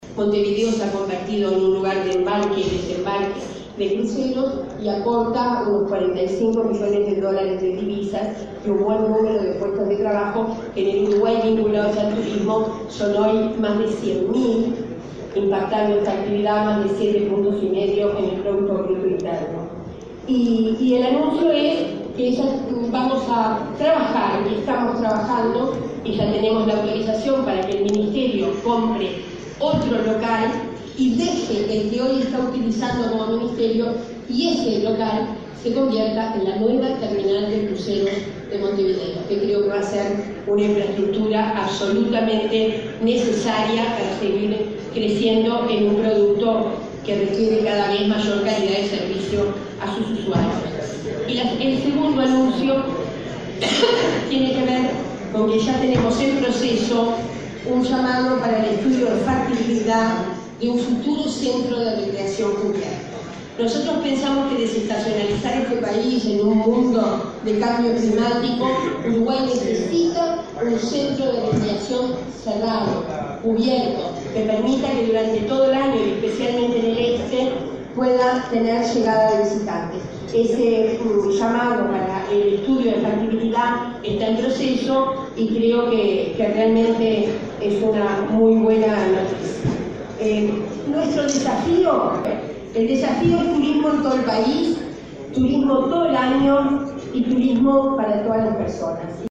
La ministra Liliam Kechichian anunció que la actual sede de esa cartera, ubicada a la entrada del puerto de Montevideo, será destinada a instalar la nueva terminal de cruceros de la capital, necesaria para atender a estos visitantes dado el crecimiento exponencial del crucerismo en el país. Kechichian también anunció en ADM, que se realizará un estudio de factibilidad para construir un centro de recreación cubierto.